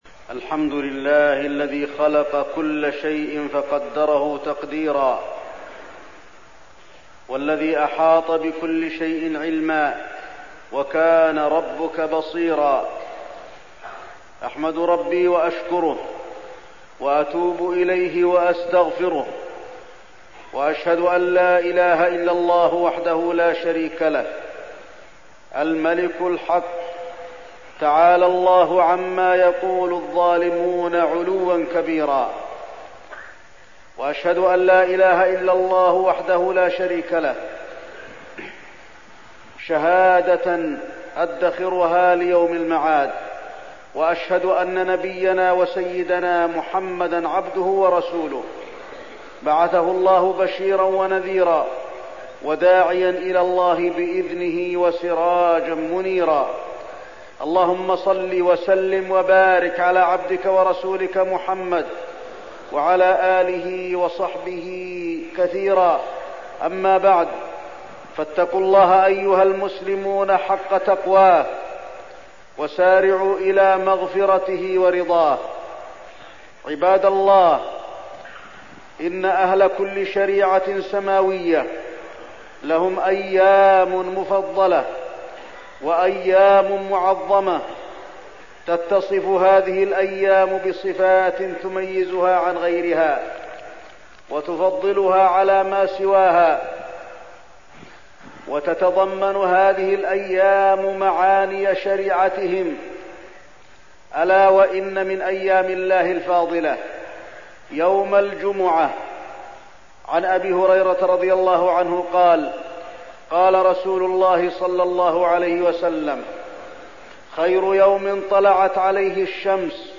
تاريخ النشر ١٤ رجب ١٤١٨ هـ المكان: المسجد النبوي الشيخ: فضيلة الشيخ د. علي بن عبدالرحمن الحذيفي فضيلة الشيخ د. علي بن عبدالرحمن الحذيفي فضل يوم الجمعة